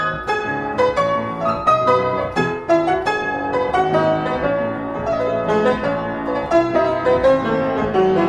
I think I fixed the problem, what I did was I turned the microphone input level to very very low like 8/100 or so and then I placed the microphone about 2ft away from the piano.
I have attached two samples one with the lower input volume non-compressed and one that has been compressed after recording.
I also played a little bit softer than usual so here are the results.